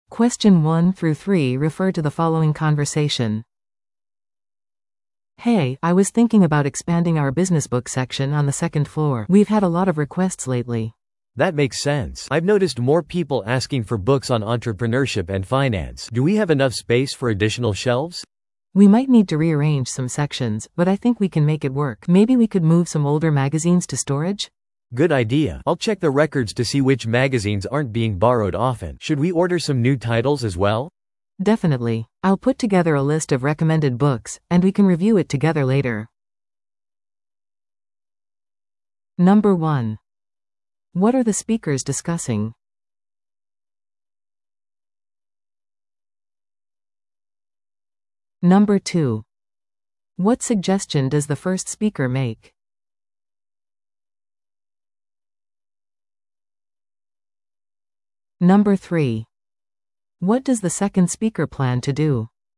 PART3は二人以上の英語会話が流れ、それを聞き取り問題用紙に書かれている設問に回答する形式のリスニング問題です。
Library staff discussion